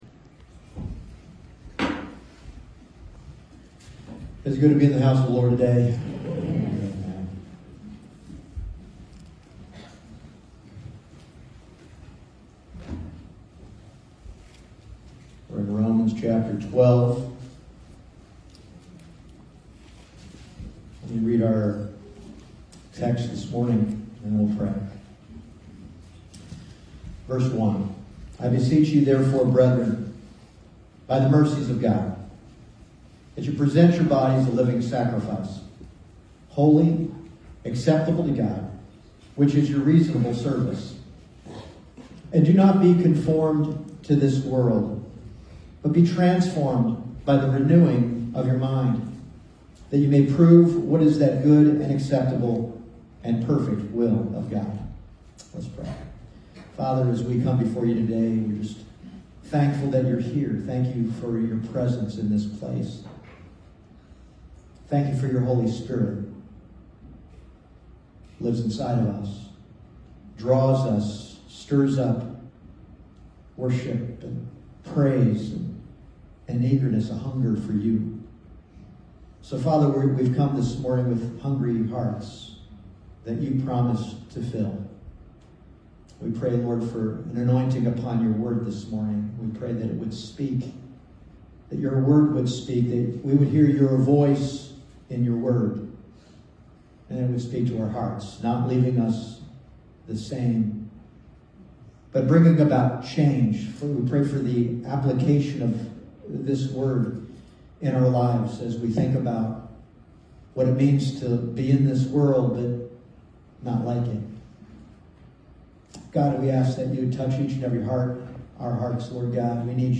Passage: Romans 12:1 Service Type: Sunday Morning